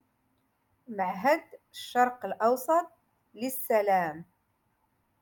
Moroccan Dialect- Rotation Six - Lesson Two Three